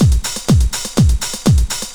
Track Master Beat 3_123.wav